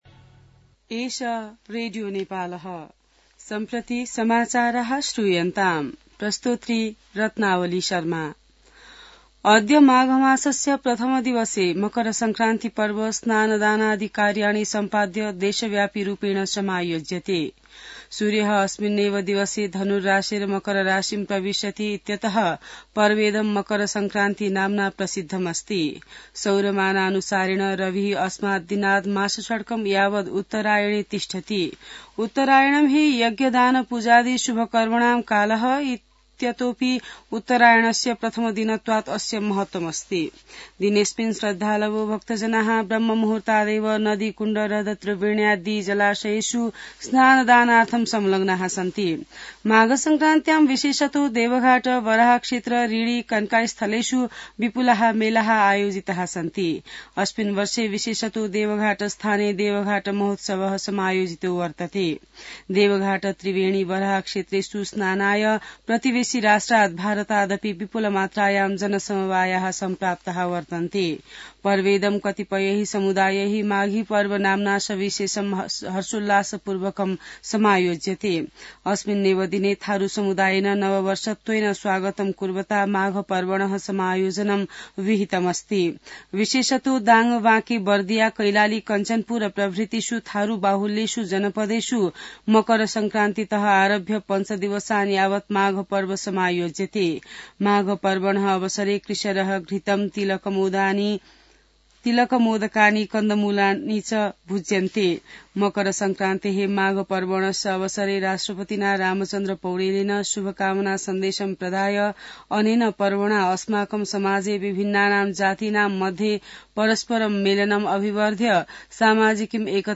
An online outlet of Nepal's national radio broadcaster
संस्कृत समाचार : २ माघ , २०८१